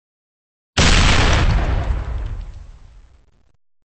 微型爆炸.MP3